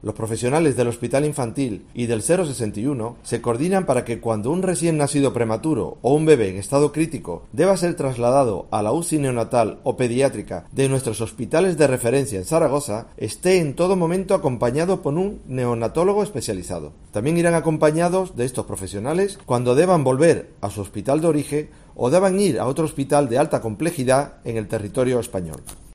El consejero de Sanidad José Luis Bancalero, explica los detalles de este nuevo servicio de neonatos